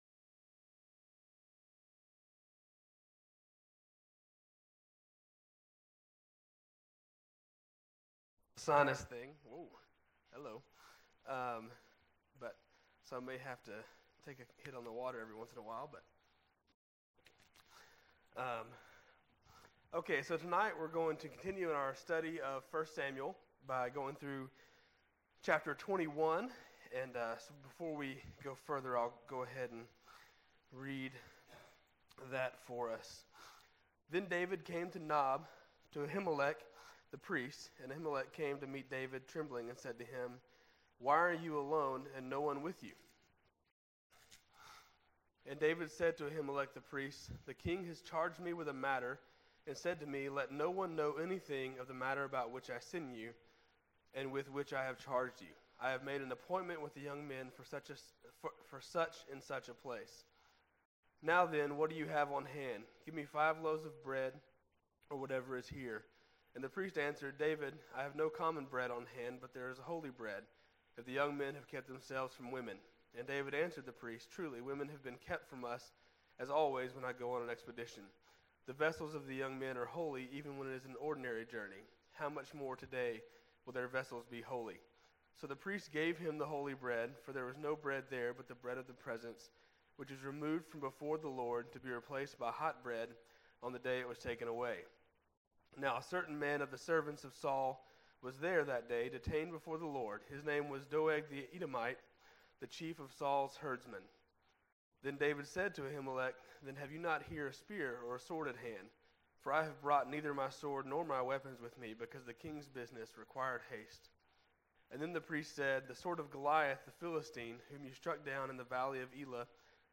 March 13, 2016 PM Worship | Vine Street Baptist Church
This was the next message in multi-part sermon series on the book of 1st Samuel.